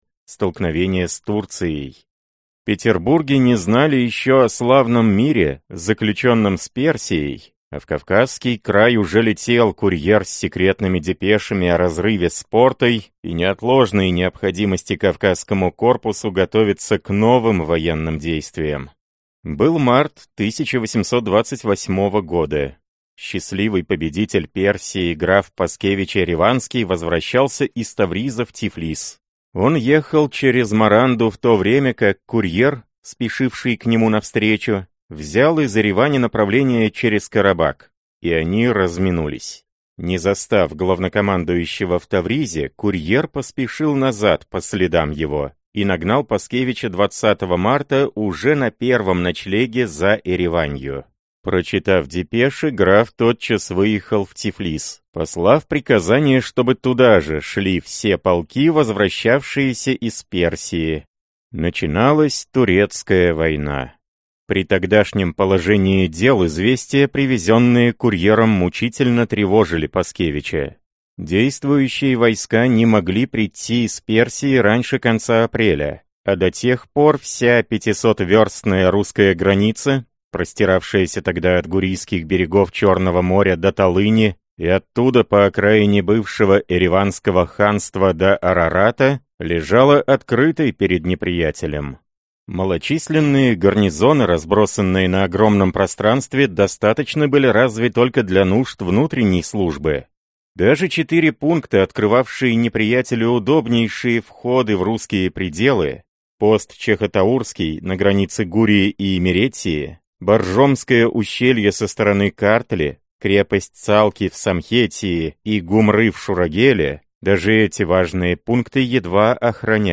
Аудиокнига Кавказская война в отдельных очерках, эпизодах, легендах и биографиях. Том 4. Турецкая война 1828–1829 гг.